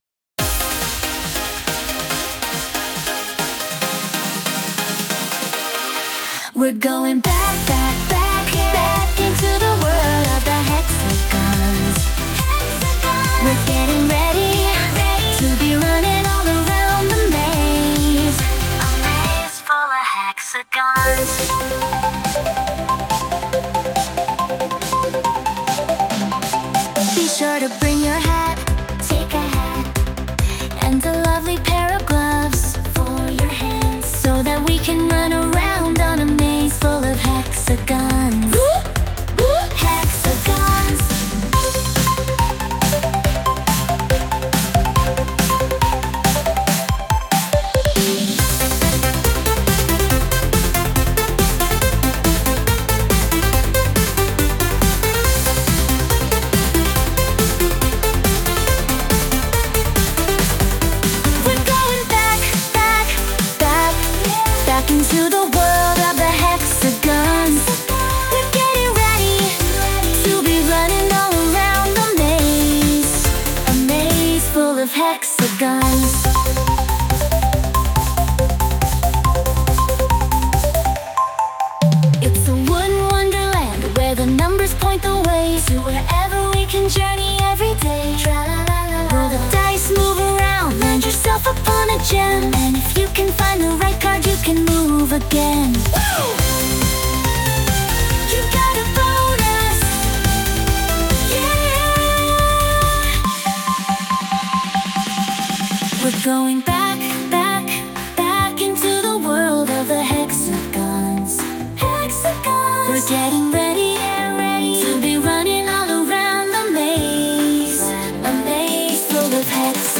Anime version
Sung by Suno